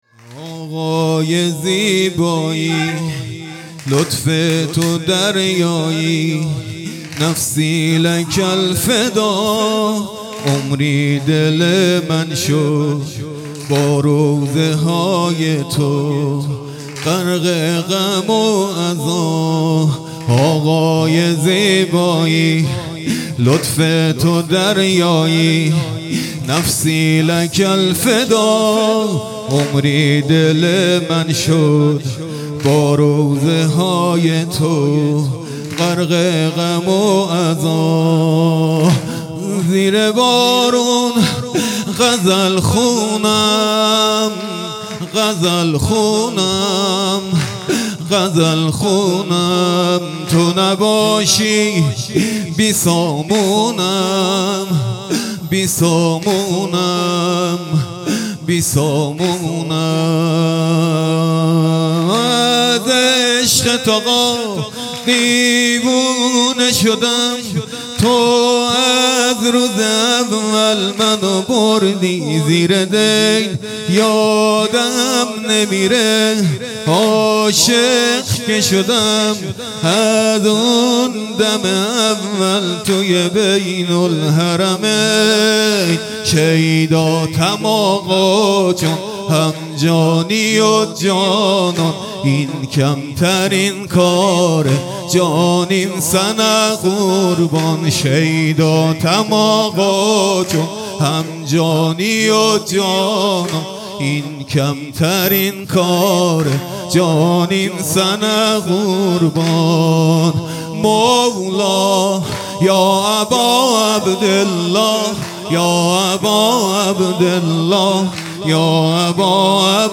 هیئت بیت العباس (ع) اسلامشهر
واحد شب هشتم محرم الحرام ۱۴۴۶